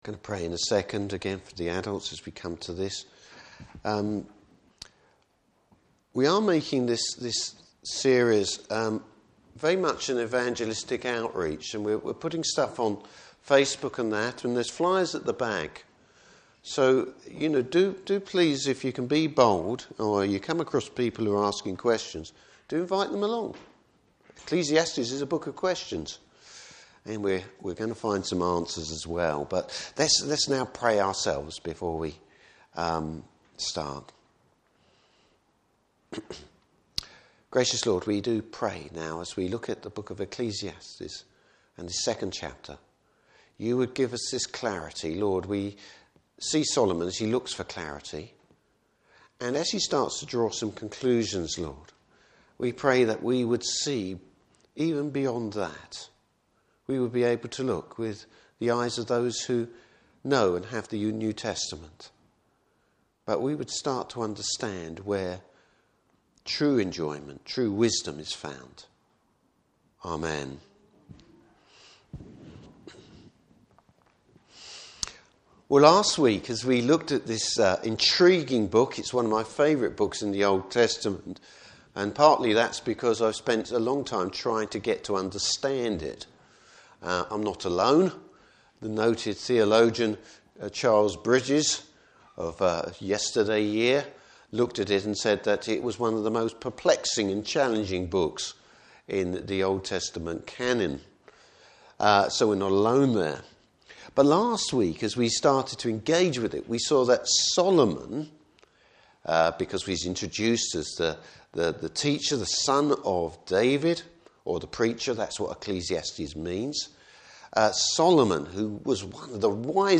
Service Type: Morning Service Bible Text: Ecclesiastes 2.